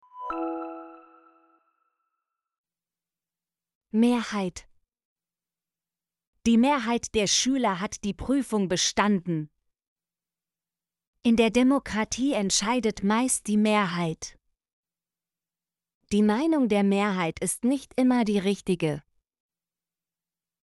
mehrheit - Example Sentences & Pronunciation, German Frequency List